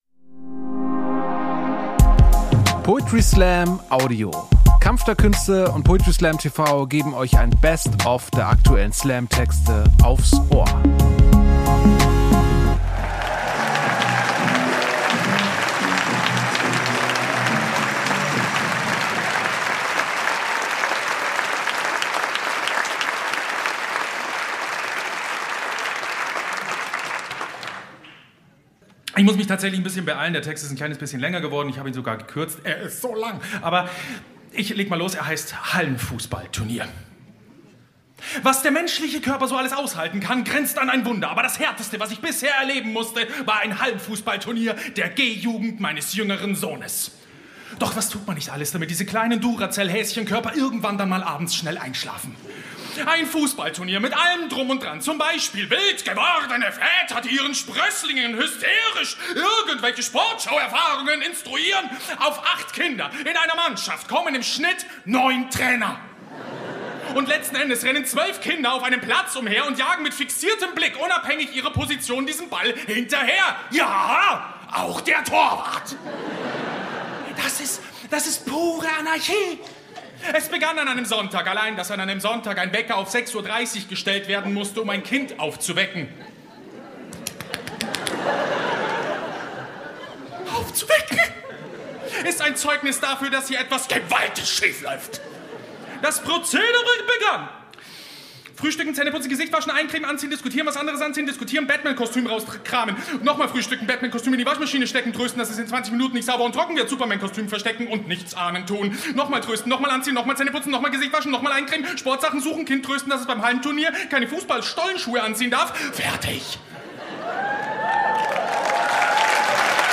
Stage: Deutsches Schauspielhaus, Hamburg